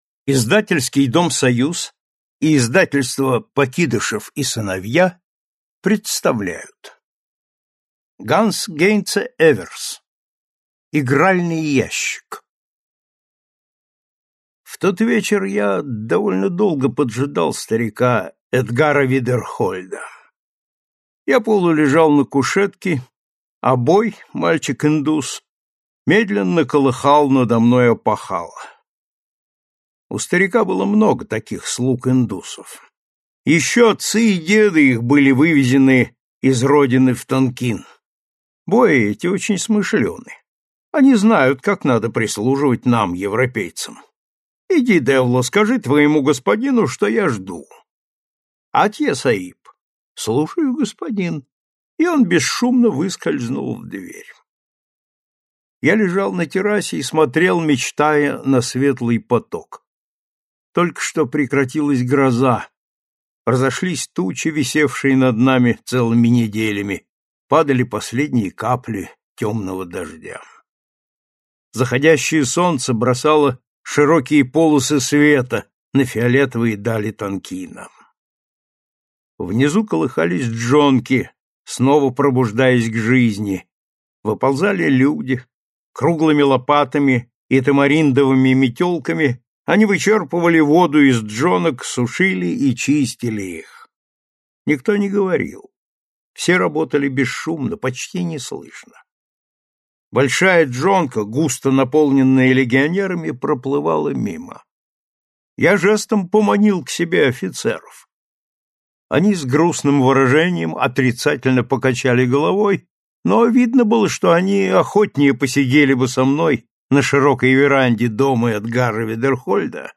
Аудиокнига Игральный ящик и другие рассказы | Библиотека аудиокниг